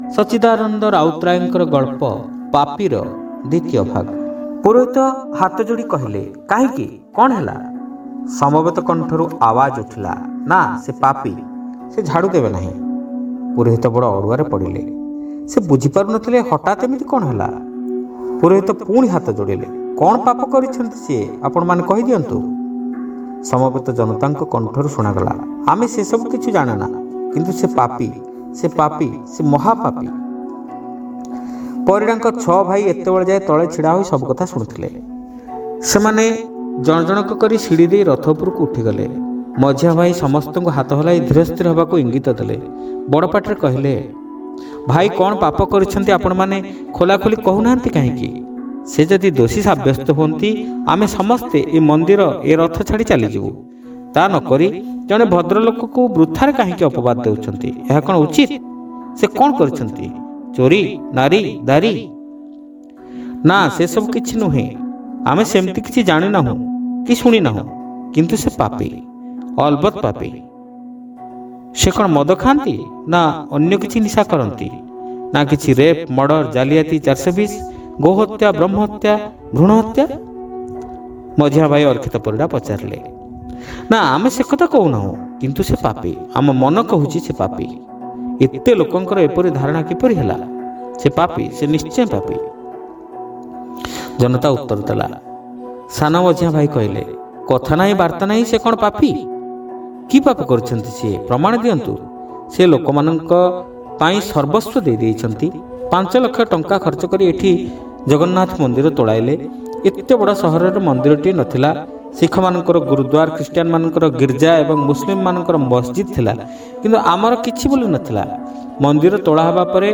ଶ୍ରାବ୍ୟ ଗଳ୍ପ : ପାପୀ (ଦ୍ୱିତୀୟ ଭାଗ)